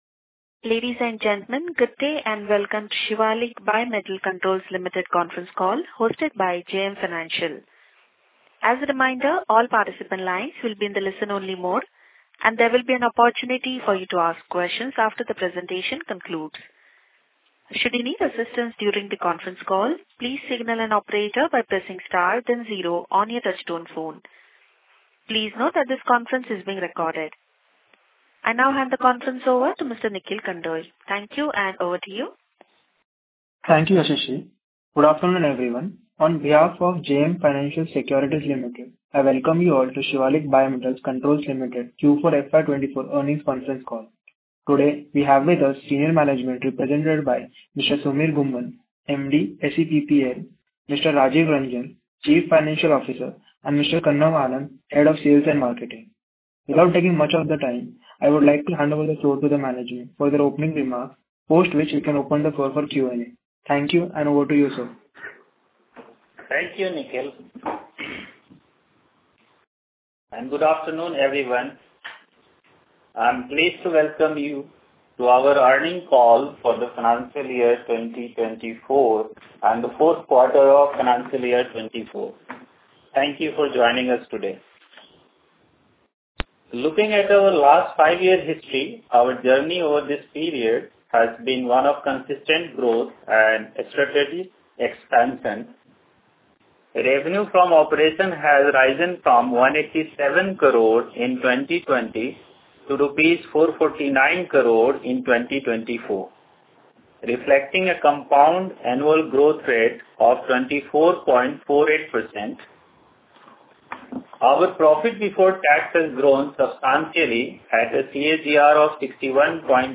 Audio Recording of Q2 & H1FY25 - Earnings Call